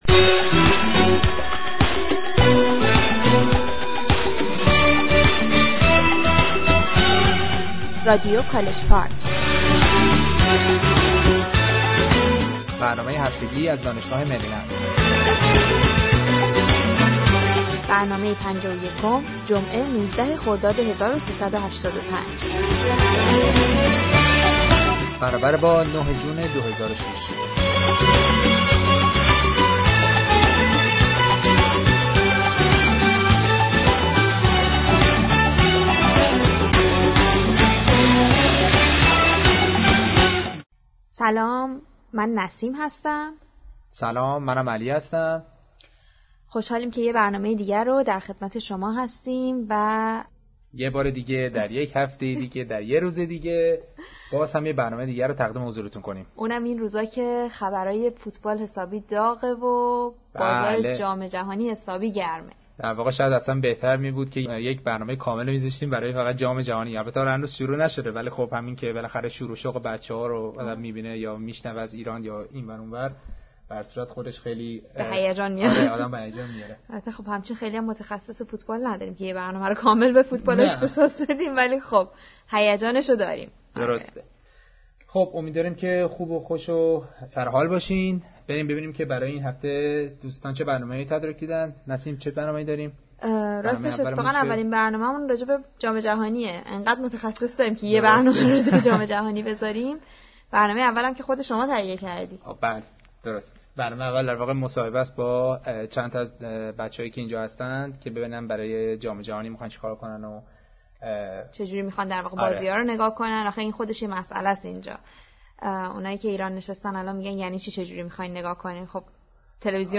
Interview About World Cup 2006